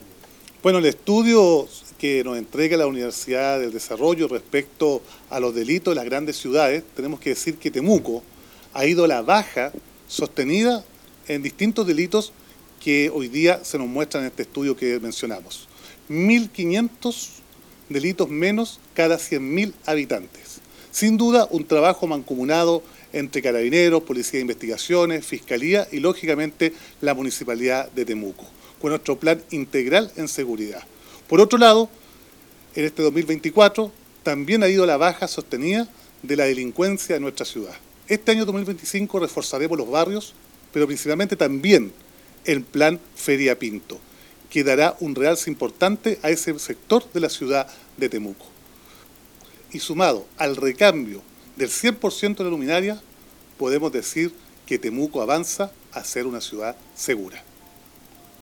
cuna-Roberto-Neira-alcalde-Temuco.mp3